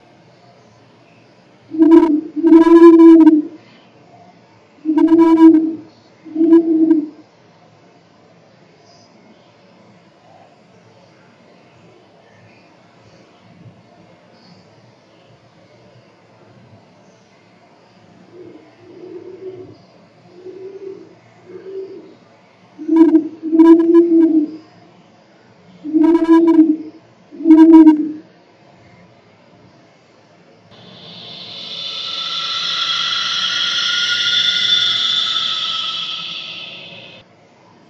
great_horned_owl_trimmed_stretched3.mp3